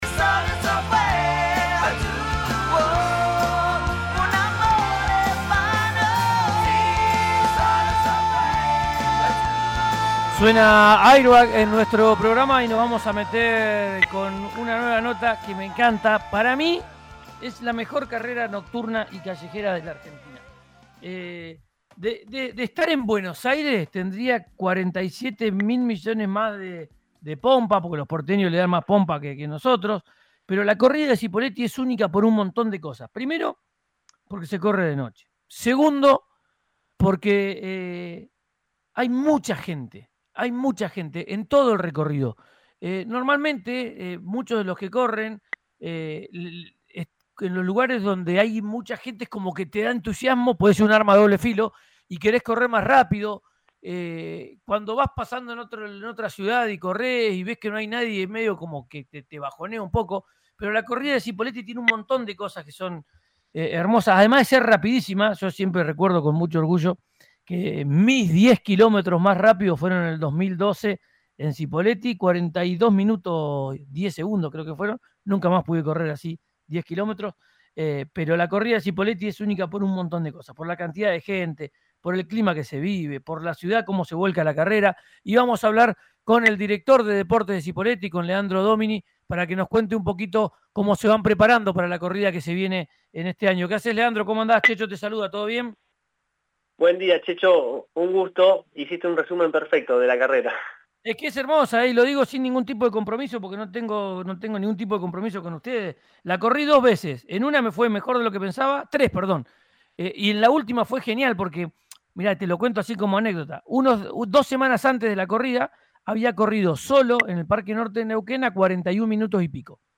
Escuchá a Leandro Domini, secretario de Deportes de Cipolletti, en RÍO NEGRO RADIO: